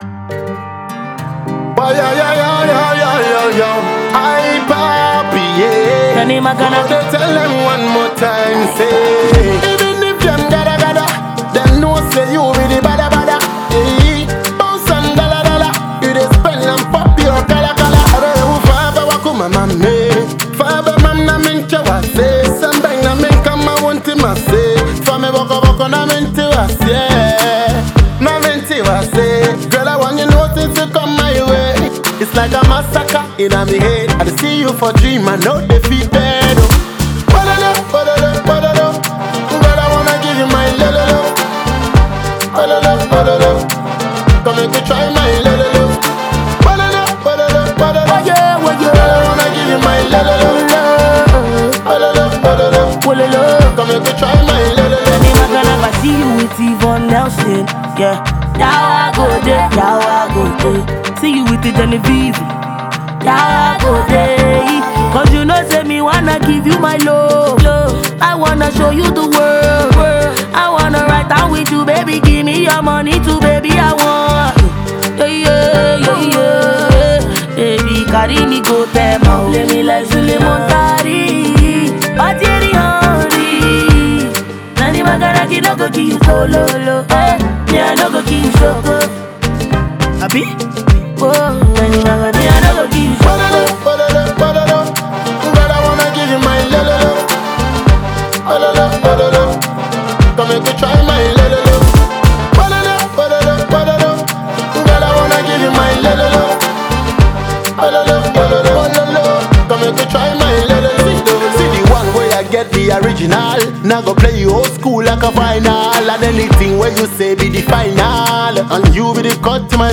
это яркая и энергичная композиция в жанре афробит